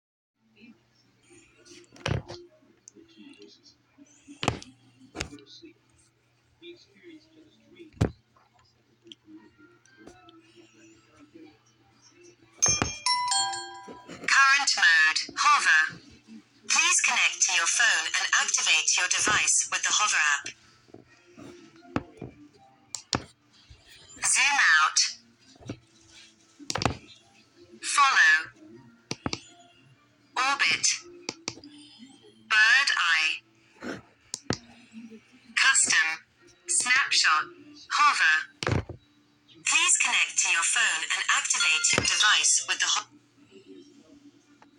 Speech telling you the flight modes of the HoverAir X1.
hoverair sounds.m4a